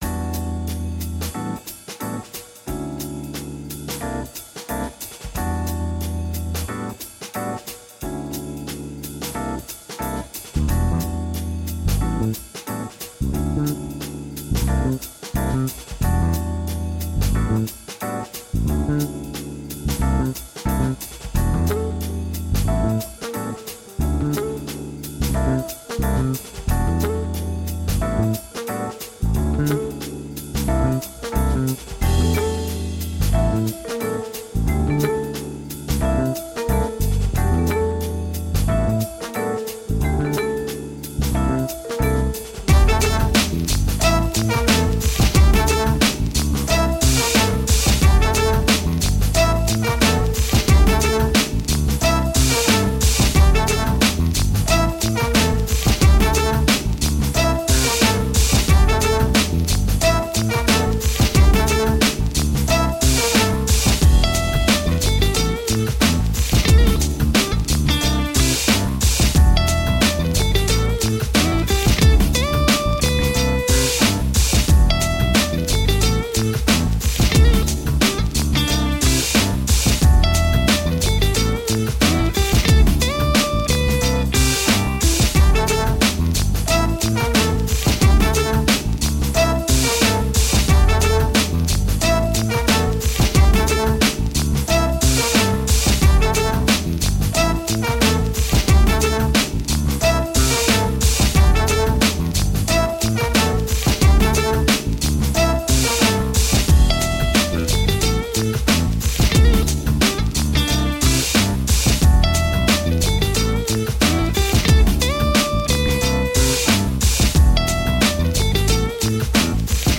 This album is both classic and modern in its sound so relax
Tagged as: Jazz, Downtempo, Funk, Funk